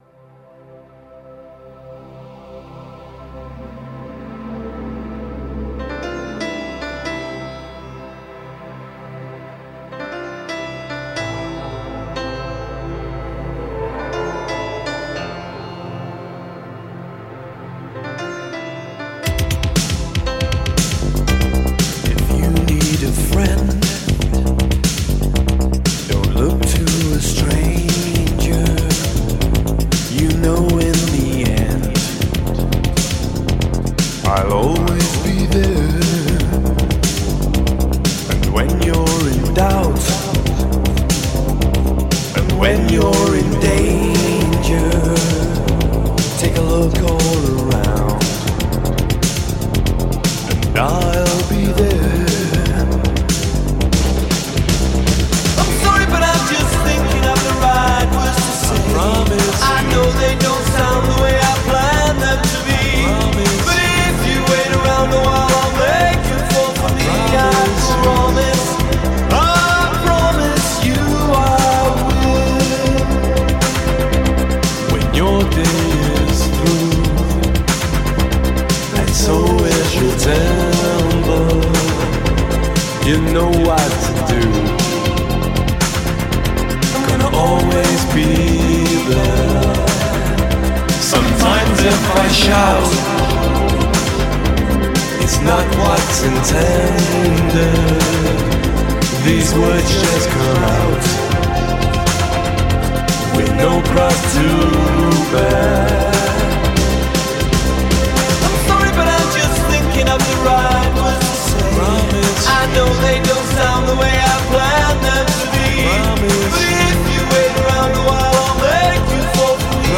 Типа Indie Pop, New Wave.